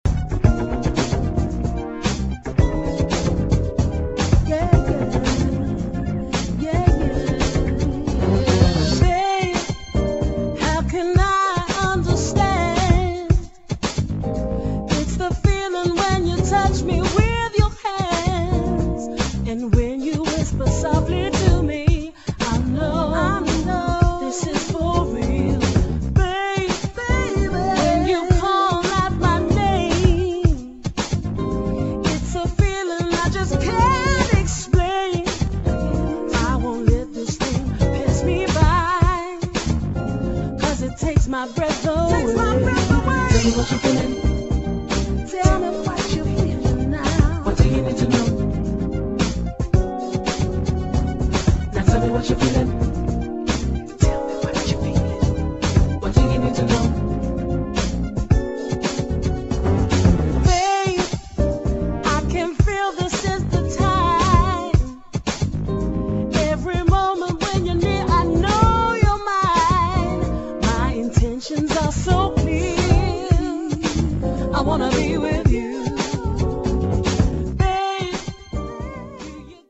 [ JAZZ / FUNK / SOUL / HOUSE ]